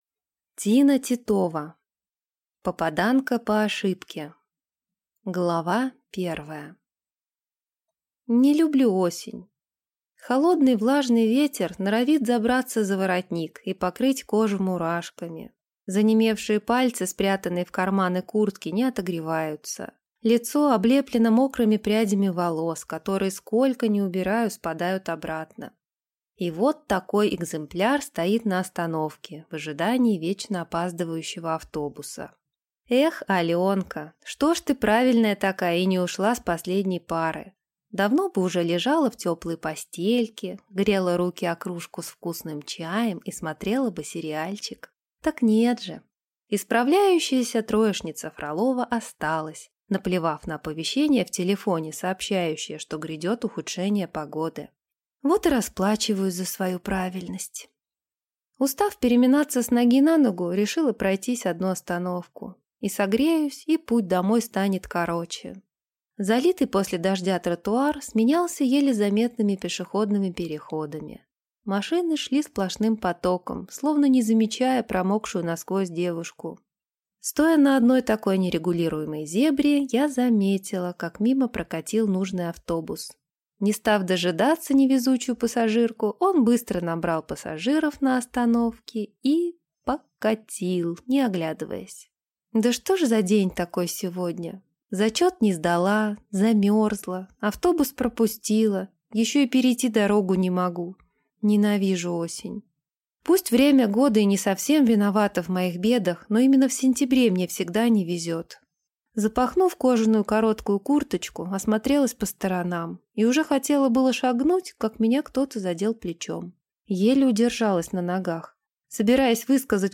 Аудиокнига Попаданка по ошибке | Библиотека аудиокниг